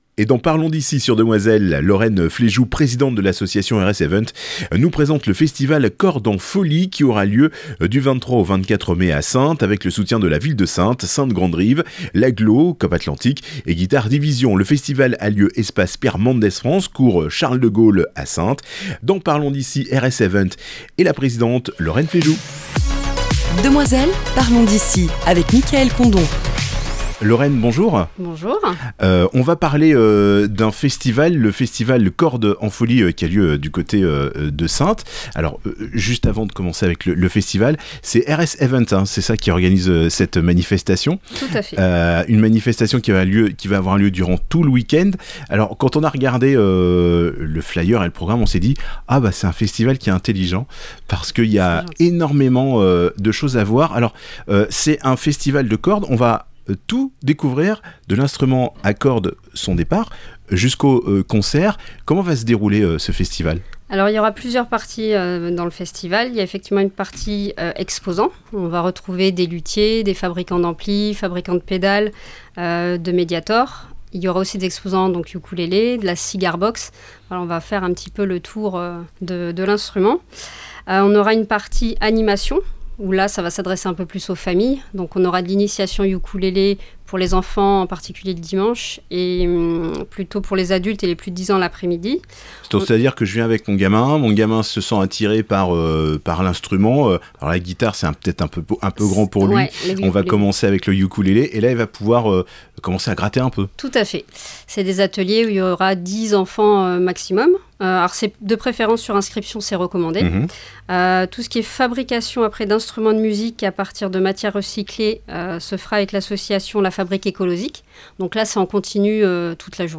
Actualités en Charente-Maritime - Demoiselle FM